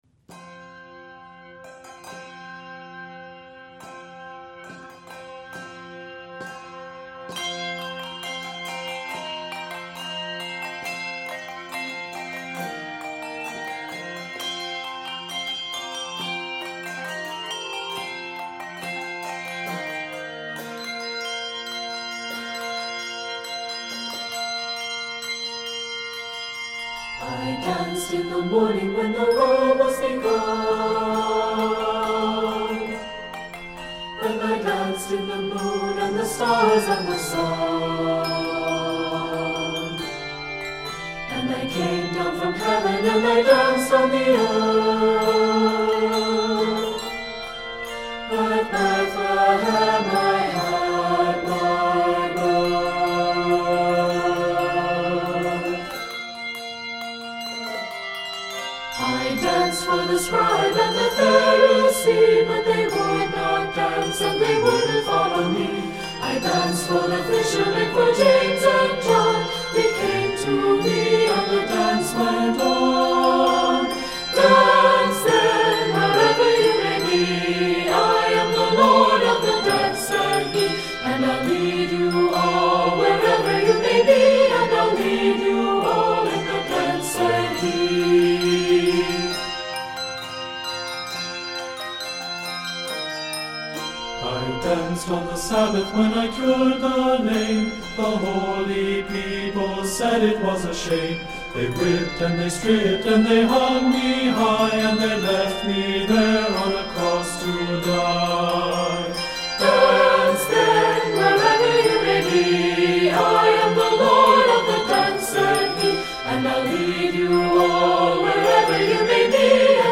This energetic and accessible “Celtic” setting
Keys of F Major and G Major.